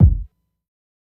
Kicks
KICK_ALONG.wav